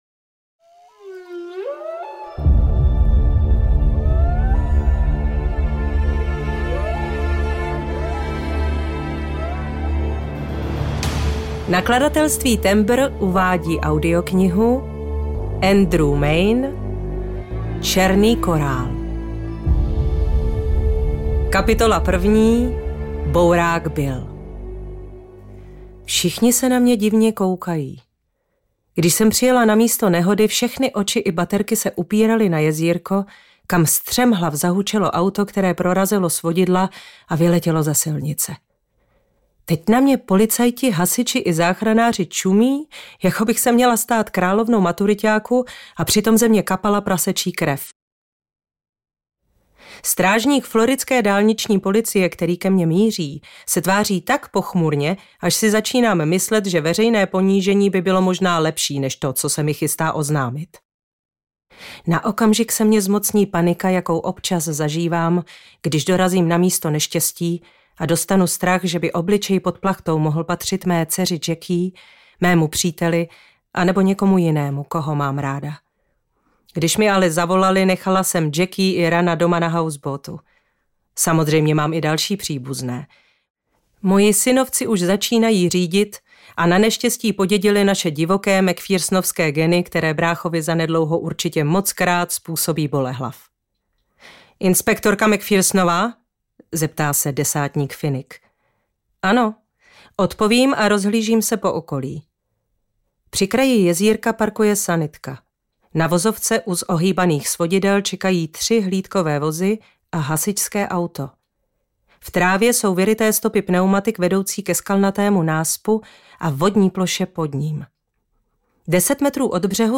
Černý korál audiokniha
Ukázka z knihy
• InterpretBarbora Kodetová